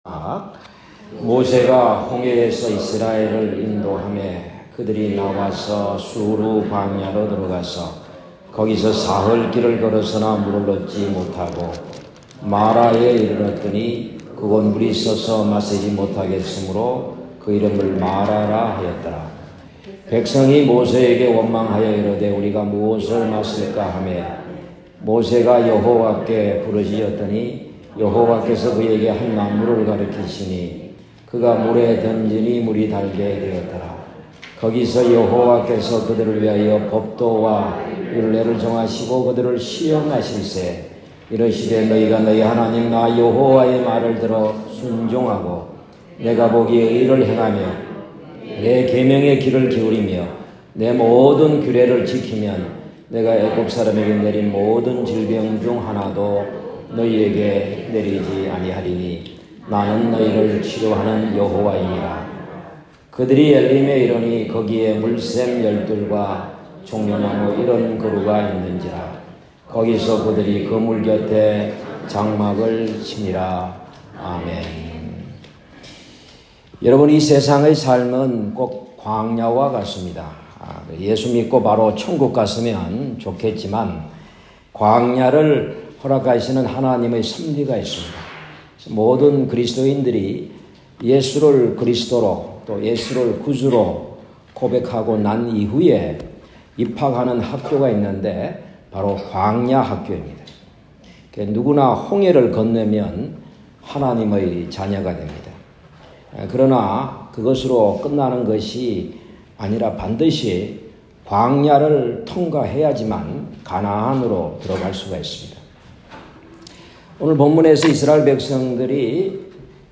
주일설교(2부)